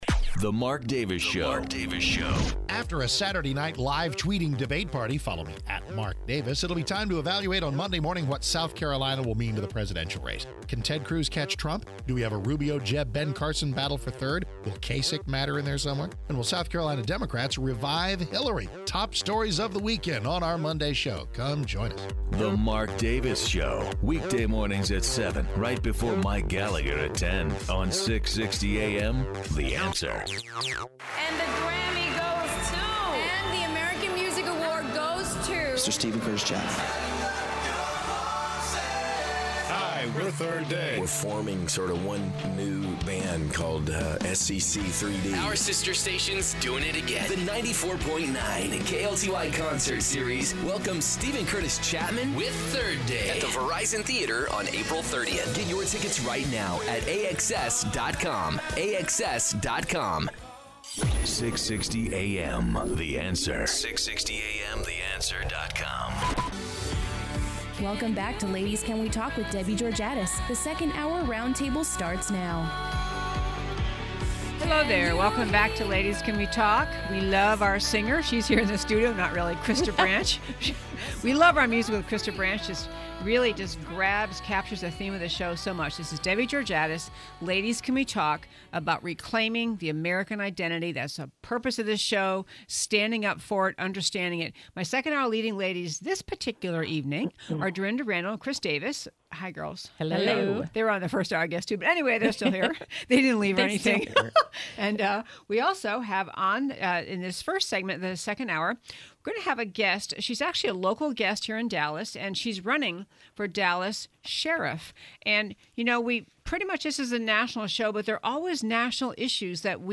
Radio Show Podcasts